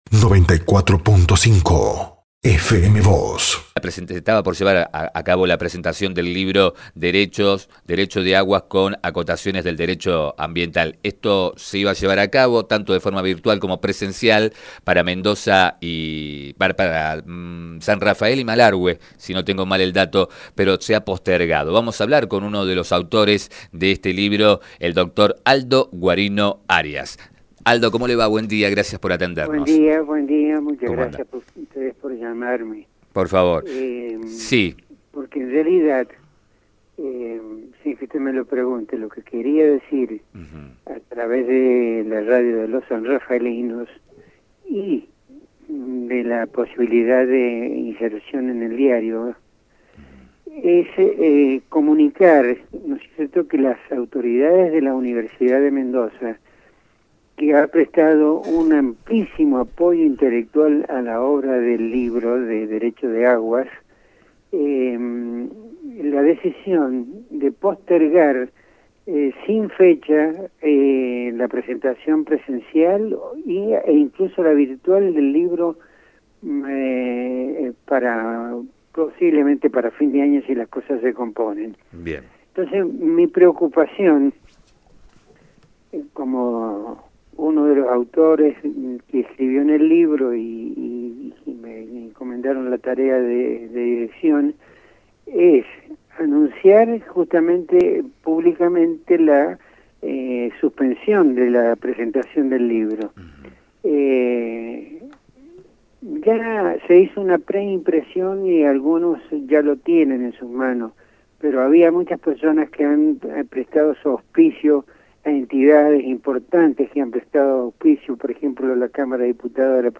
En diálogo con FM Vos (94.5) y Diario San Rafael